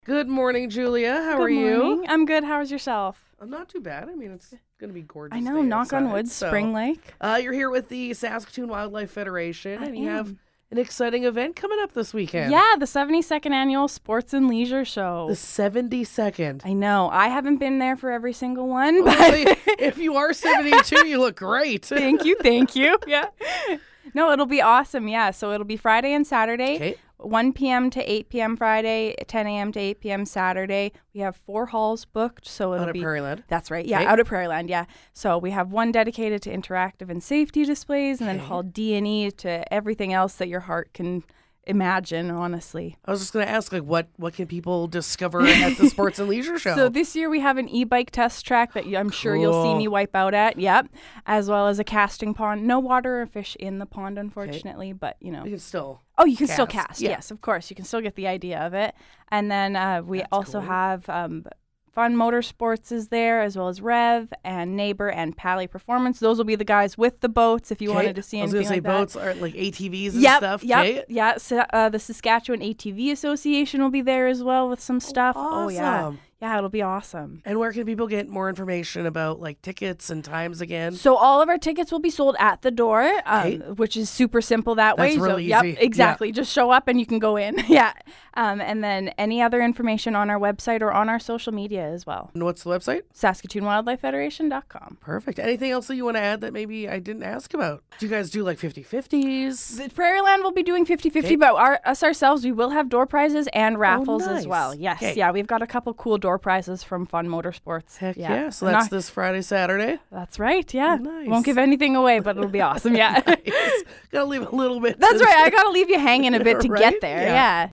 Interview: 72nd Sports & Leisure Show